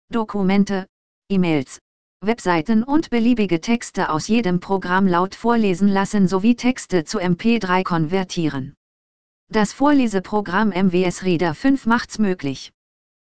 Diese Text to Speech Stimme wird mit MWS Reader ab Version 5.5 unter Windows 10 Deutsch automatisch freigeschaltet